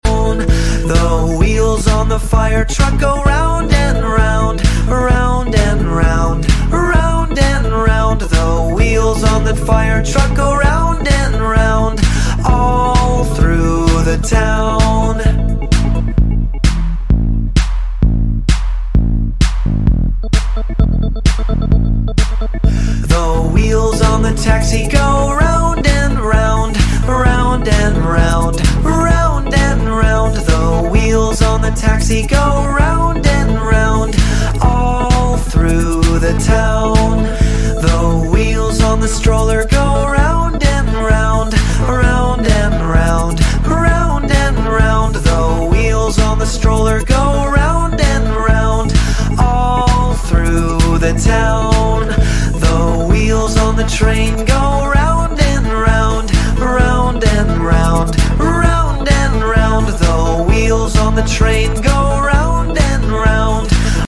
Music for Kids ｜ Kindergarten Songs for Children